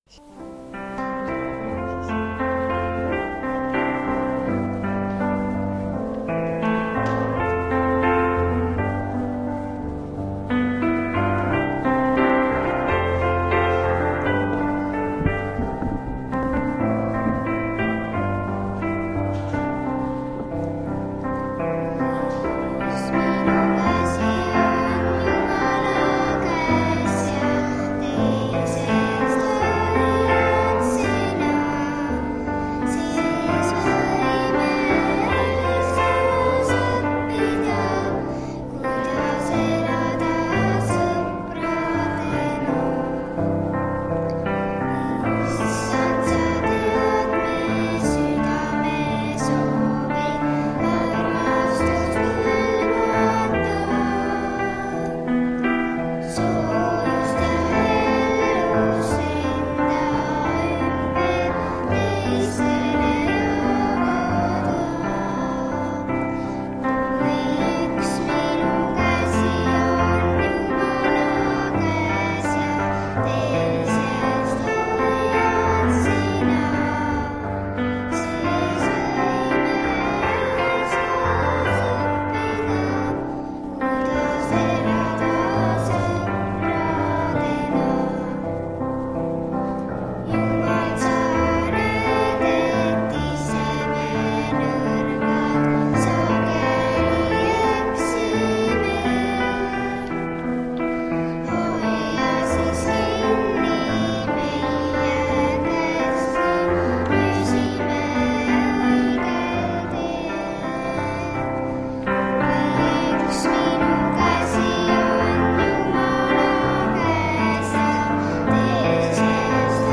Jutlused
Enne jutlust laulavad lapsed ka ühe laulu.